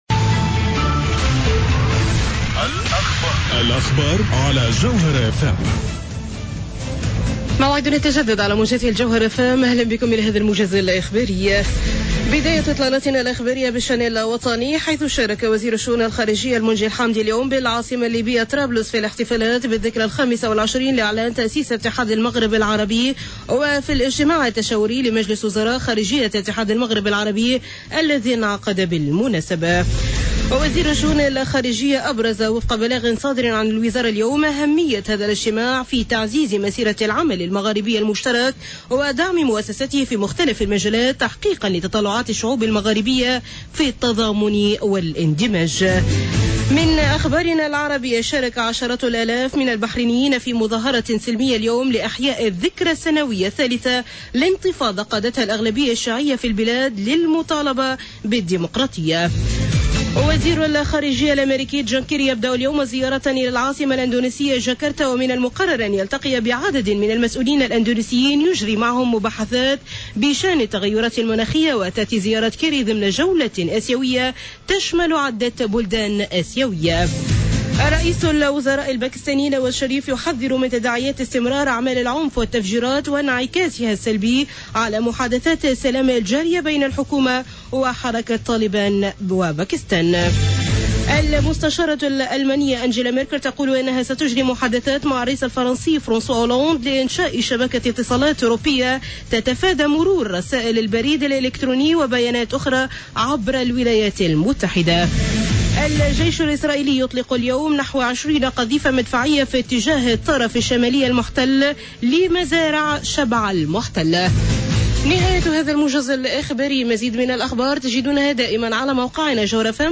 موجز الأخبار